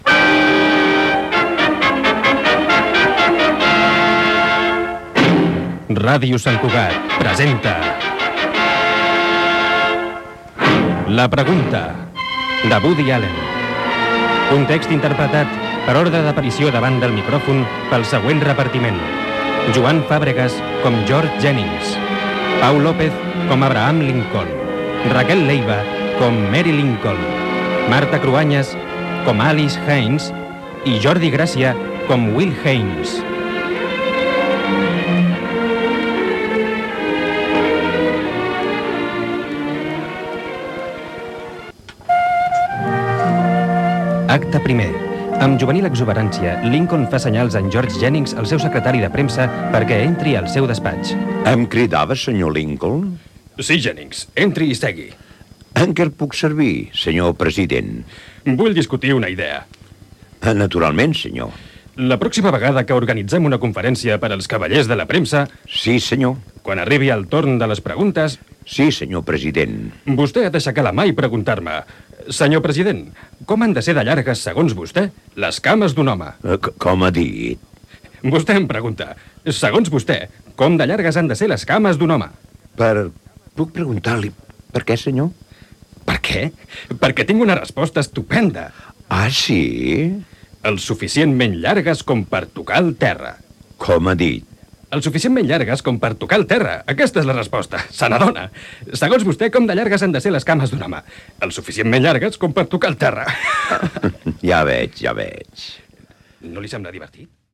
"La pregunta" de Woody Allen, careta del dramàtic radiofònic i minuts inicials de l'acte primer.
Ficció